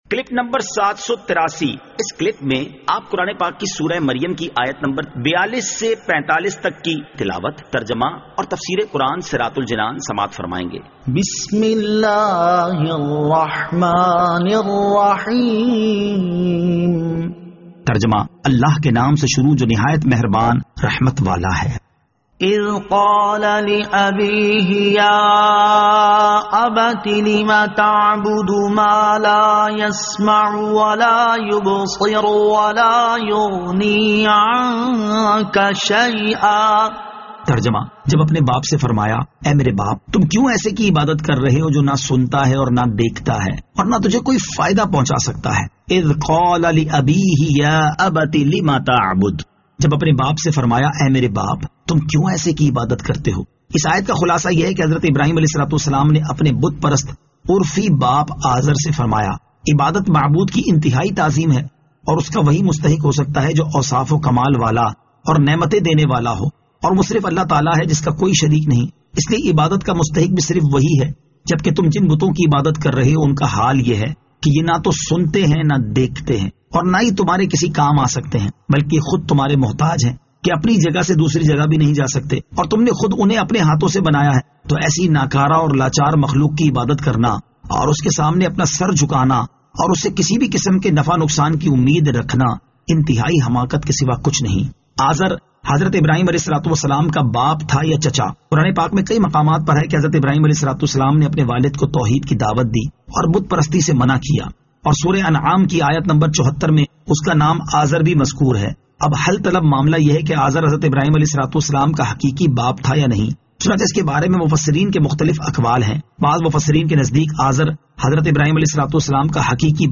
Surah Maryam Ayat 42 To 45 Tilawat , Tarjama , Tafseer